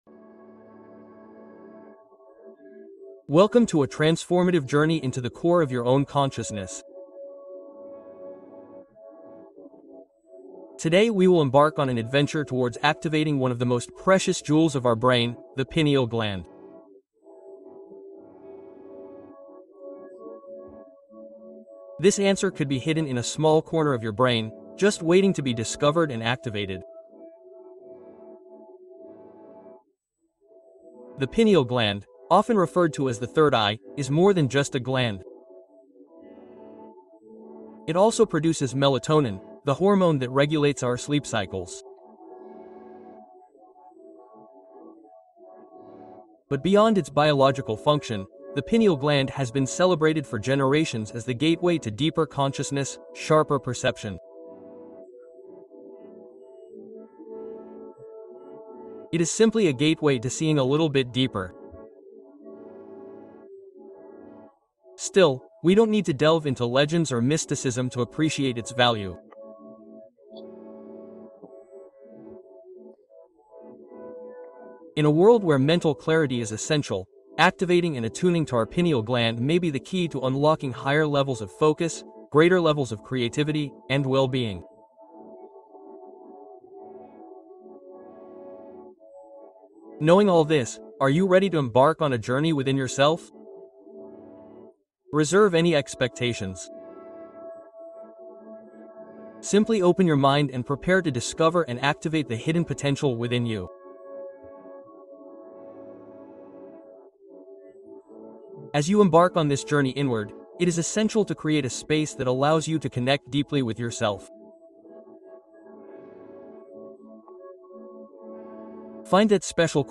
Activación del Tercer Ojo en Relajación Profunda: Meditación Guiada